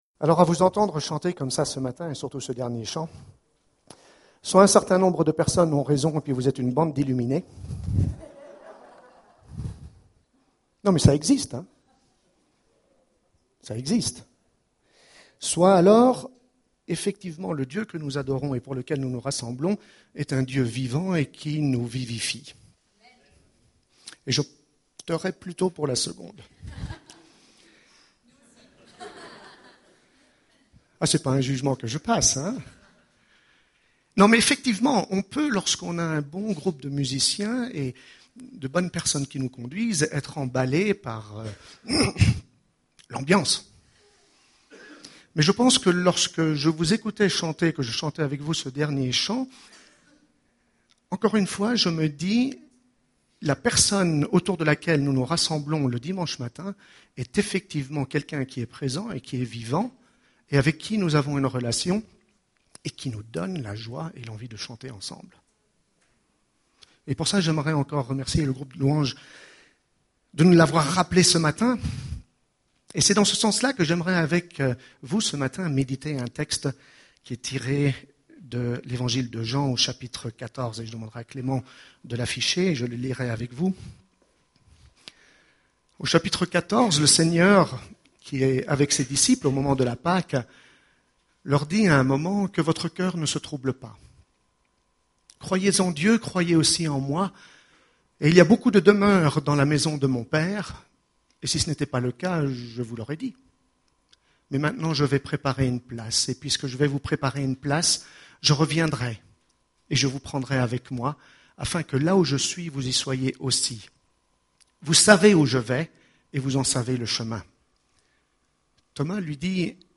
Culte du 18 mai